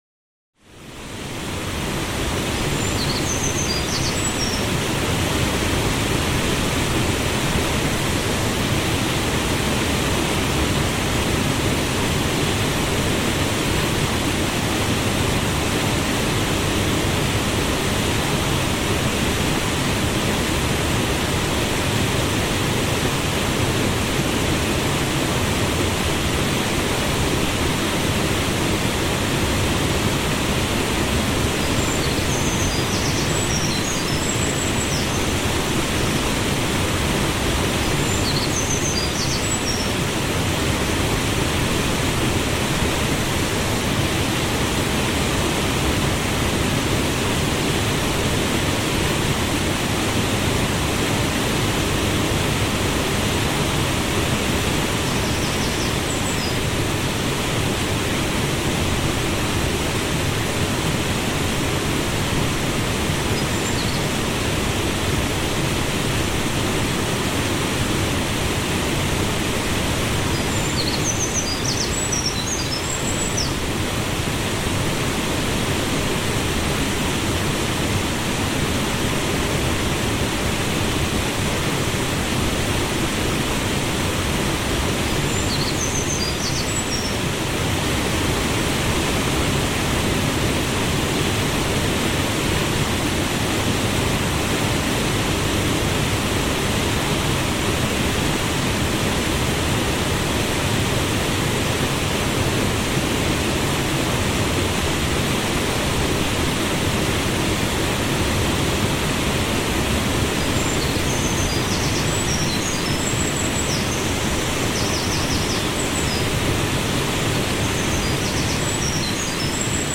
Tenkawa-Wasserfall ASMR Naturrauschen & Waldesruhe in Hi-Res